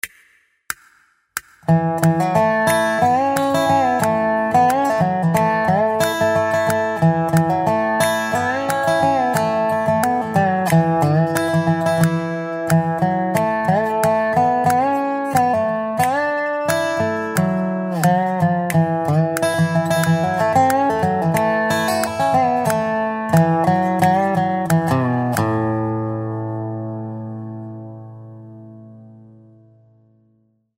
Nástroj: Dobro
Tónina: A
Styl: Bluegrass